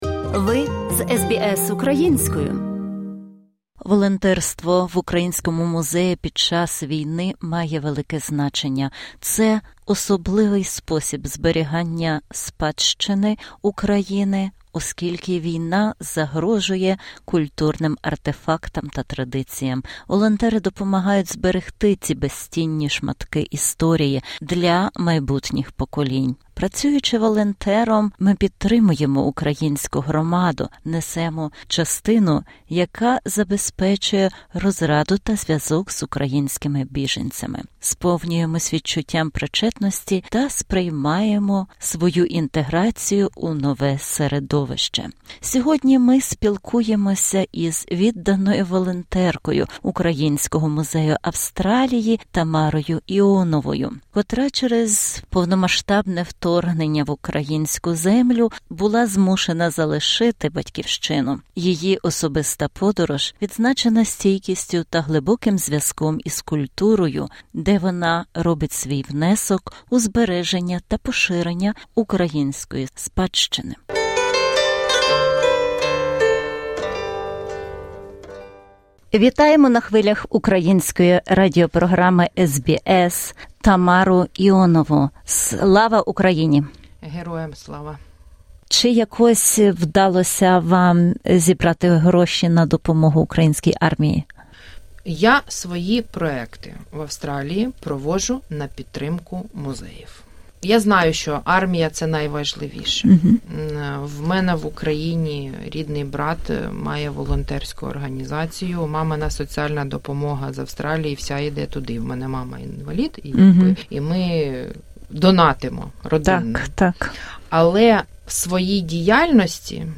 інтерв’ю